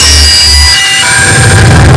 sonarPingWaterVeryCloseShuttle3.ogg